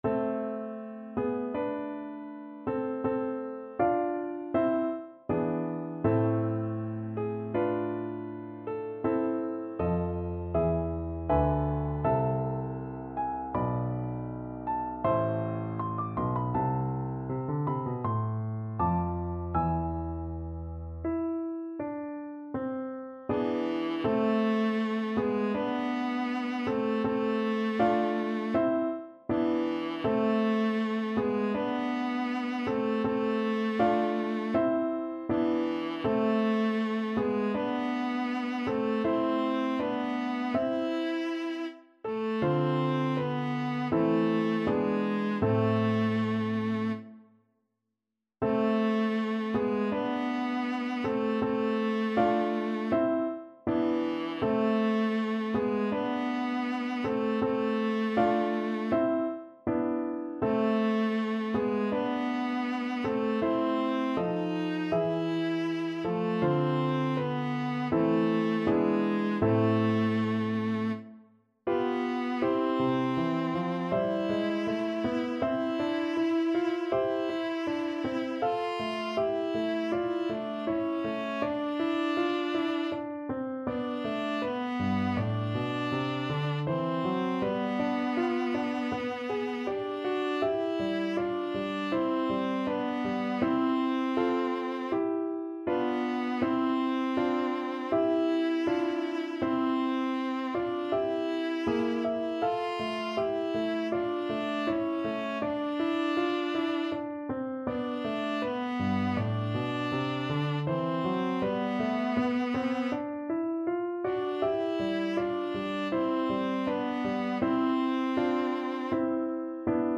Viola Classical
Key: A minor (Sounding Pitch)
Tempo Marking: Andante =c.80
Time Signature: 4/4
Instrument: Viola